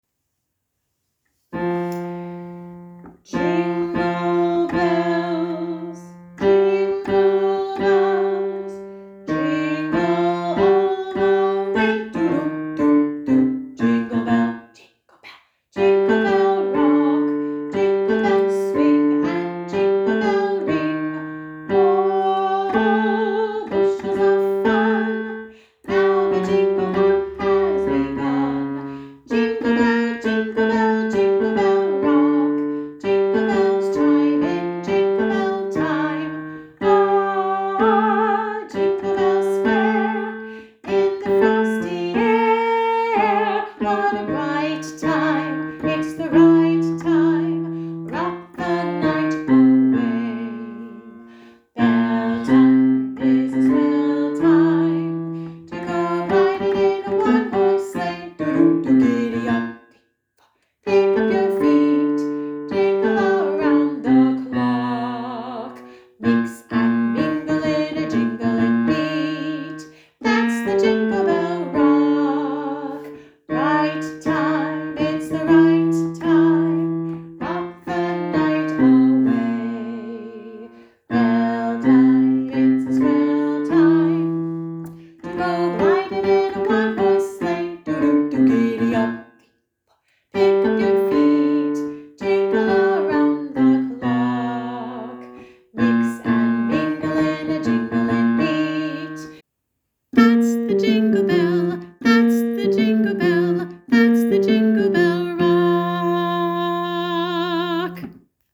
Harmony sing along tracks
lower voice